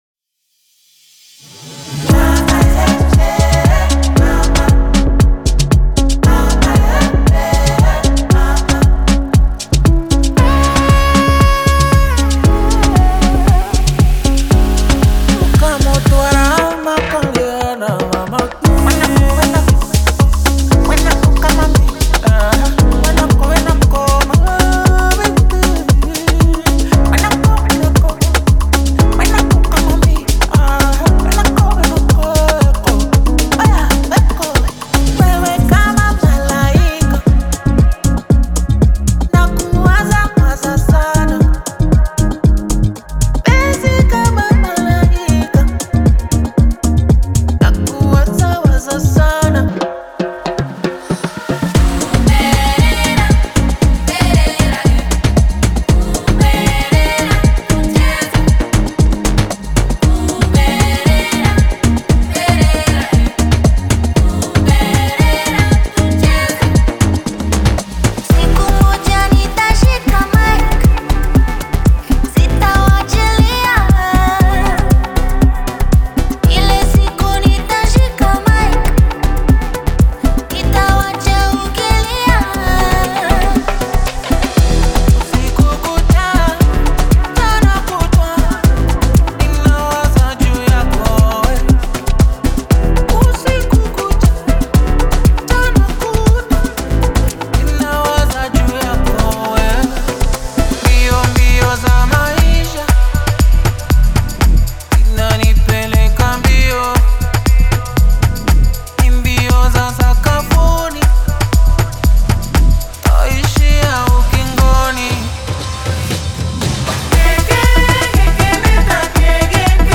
本格的なケニアのボーカル。
リアルな感情。
すべてのボーカルは約8小節を基準に構成され、115から124BPMにテンポマッチされ、キー表記付きで提供されます。
オーディオデモはラウドでコンプレッションがかかり、均一に聴こえるよう処理されています。
Genre:Afro House
322 solo vocal loops (dry + wet)
161 choral vocal loops (dry + wet)